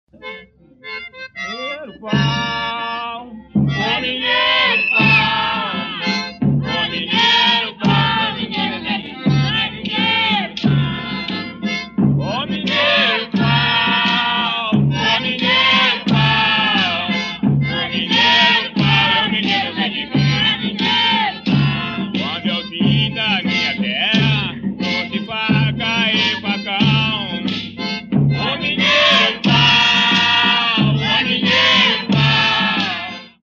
Mineiro-pau
Dança de conjunto executada por homens, cada um deles levando um ou dois bastões de madeira, desenvolvida em círculo ou em fileiras que se defrontam. Os dançarinos, voltados de frente para seus pares, realizam uma coreografia totalmente marcada pelas batidas dos bastões no chão. O acompanhamento musical é feito com sanfona de oito baixos, bumbo, caixa, triângulo, chocalho, pandeiro. Ocorre no Amazonas, em Minas Gerais e no Rio de Janeiro, geralmente no carnaval, embora possa aparecer em outras ocasiões.
autor: Mineiro-pau de Santo Antôno de Pádua, data: 1975